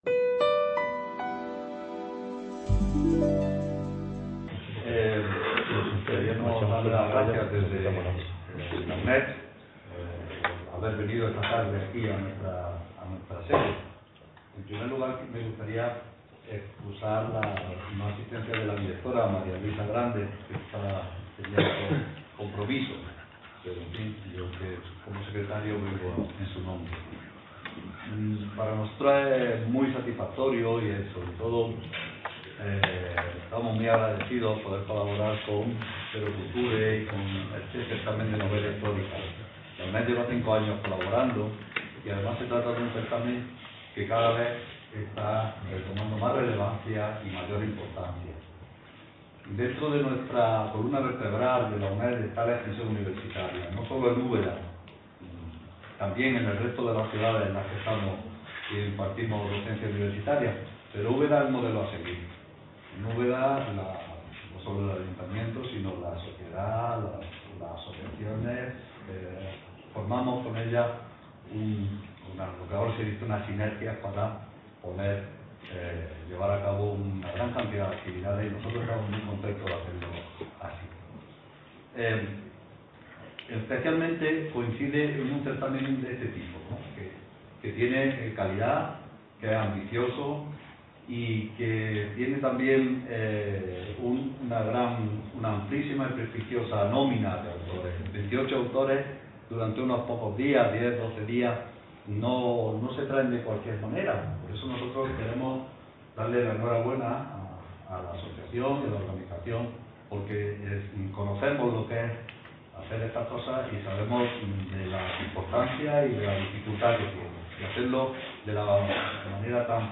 X edición del Certamen Internacional de Novela Histórica "Ciudad de Úbeda"